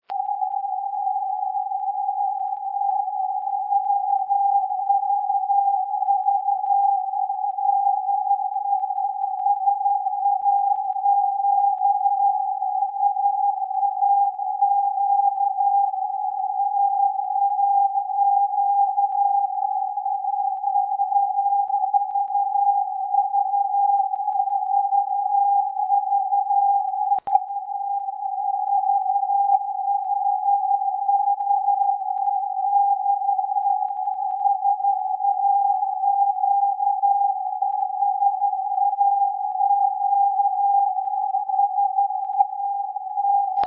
After some antenna direction the SAQ signal pops out, click here for the audio